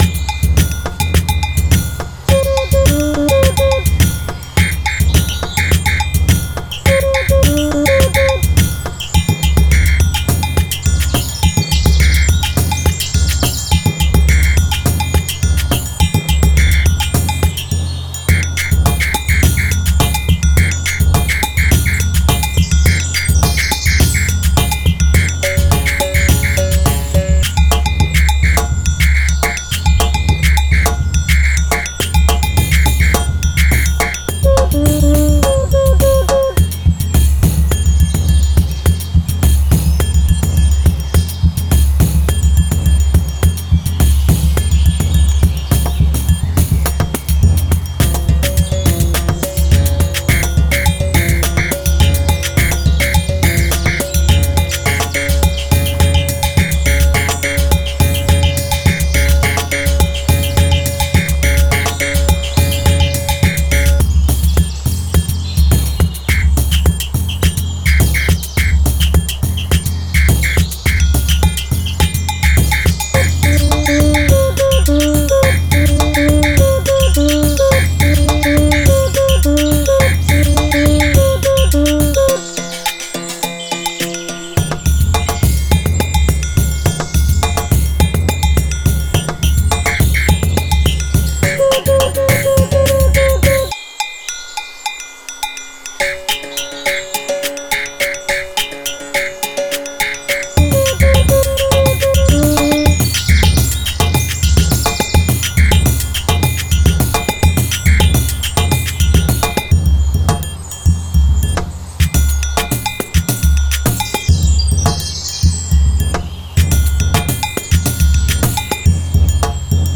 Genre:South American
伝統的なブラジルのパーカッションループを豊かに収録したこのコレクションは、ブラジルの熱気、リズム、そしてスピリットをそのままスタジオへと届けてくれます。
すべてのループは扱いやすい105 BPMで統一されており、ジャンルを超えて自在にフィットします。
アゴゴの金属的な響きからスルドの大地のような低音まで、すべての楽器は高品位な機材で収録されており、演奏の質感とニュアンスが忠実に再現されています。
ビリンバウの緊張と解放、クイーカの催眠的なパルス、パンデイロのきらめくエネルギーを感じてください。
トライアングルでスウィングを、ヘボロで推進力を、カシシやホイッスルの鮮やかな音色でグルーヴにアクセントを加えましょう。
30 Agogo Bell Loops
30 Cuica Loops
30 Surdo Loops